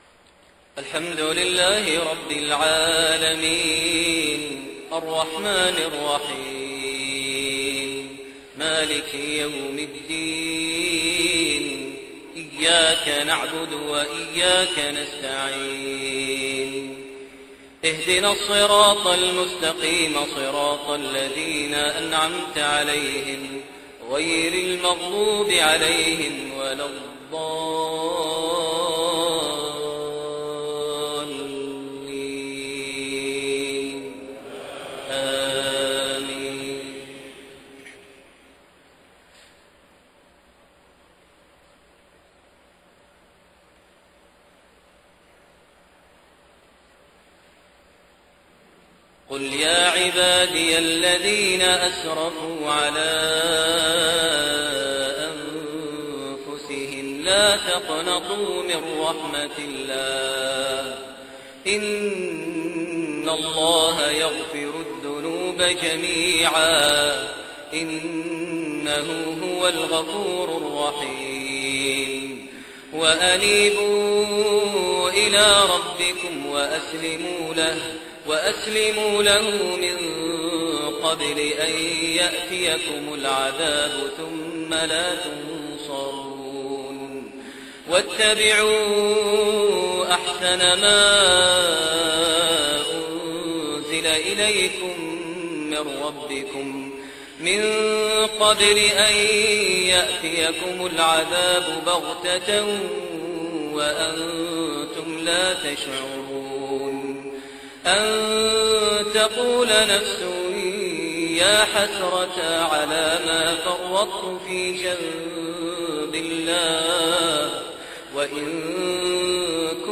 Isha prayer surah Az-Zumar > 1430 H > Prayers - Maher Almuaiqly Recitations